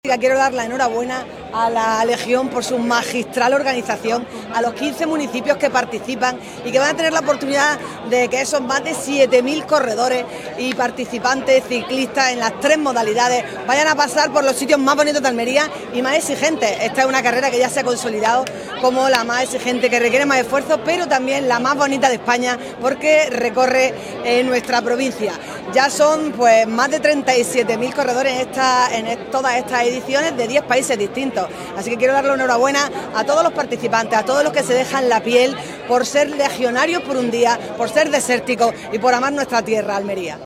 ALCALDESA-SALIDA-DESERTICA-2025.mp3